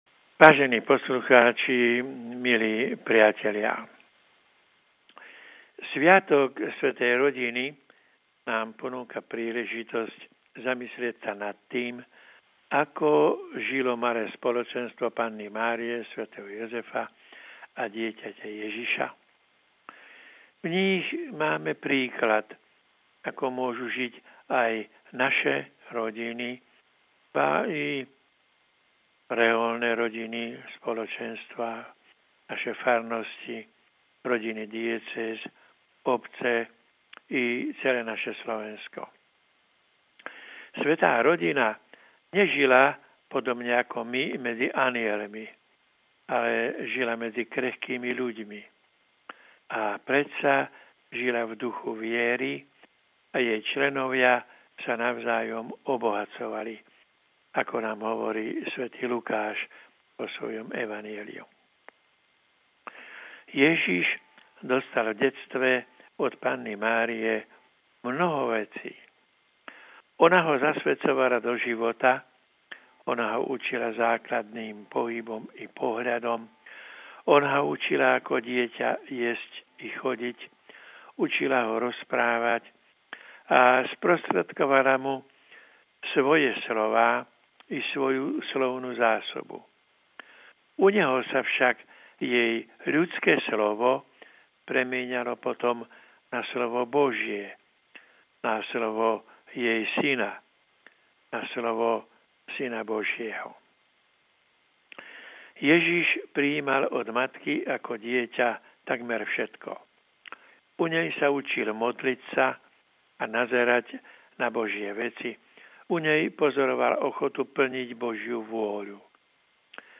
Príhovor kardinála Jána Chryzostoma Korca na sviatok Svätej rodiny
Dnes Vám prinášame príhovor kardinála Jána Chryzostoma Korca, emeritného biskupa nitrianskej diecézy.